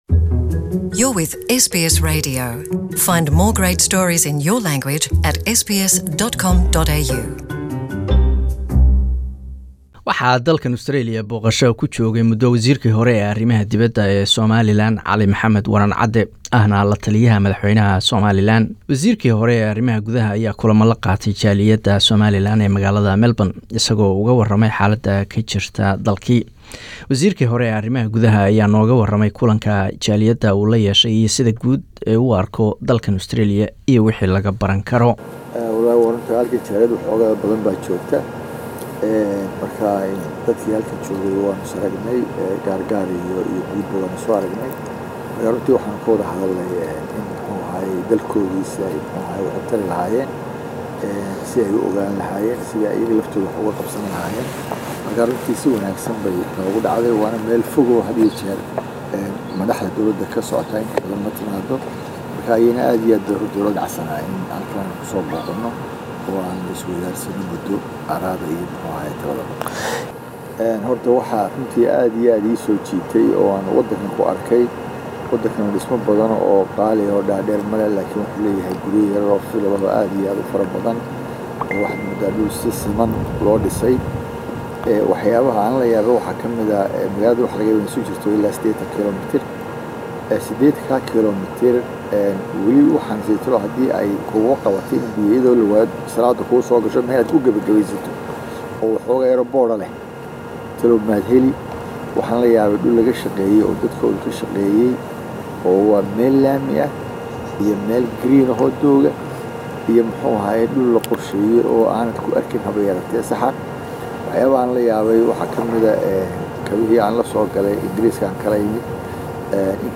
Waraysi: wasiirkii hore arimaha gudaha Somaliland